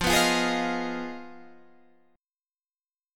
Listen to Fm7b5 strummed